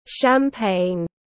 Rượu sâm-banh tiếng anh gọi là champagne, phiên âm tiếng anh đọc là /ʃæmˈpeɪn/
Champagne /ʃæmˈpeɪn/